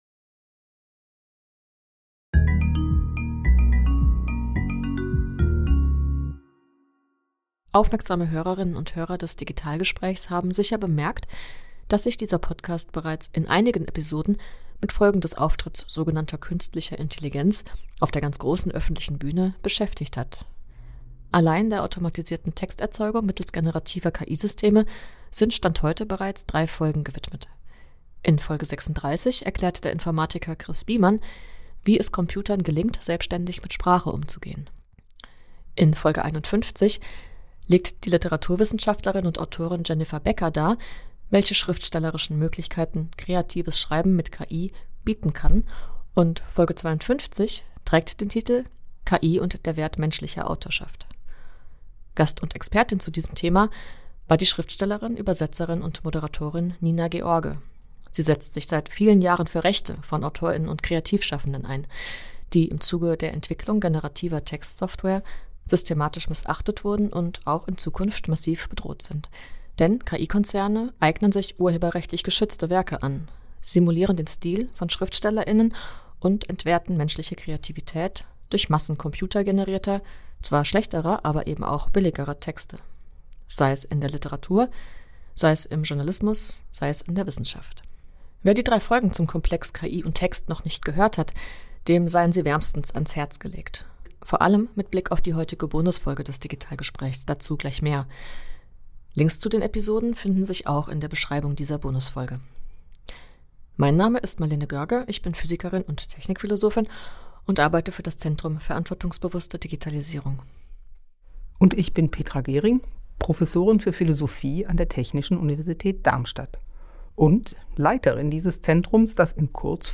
Als einer der Gäste des Symposiums sprach Paul Nemitz, Chefberater der Europäischen Kommission, über rechtliche Möglichkeiten und Perspektiven des Schutzes der Interessen von Autor:innen und Kreativschaffenden angesichts der Übermacht von Software-Unternehmen. Der Vortrag – gerichtet an Betroffene, die nach Strategien und Hebeln suchen, ihre Rechte geltend zu machen – gibt Einblicke in die Welt der Lobbyarbeit auf EU-Ebene, enthält Ratschläge und benennt Notwendigkeiten, und er macht die gesamtgesellschaftliche Perspektive klar, vor der die legitimen Interessen einzelner Kreativer und ihrer Verbände zu einer demokratischen Angelegenheit werden.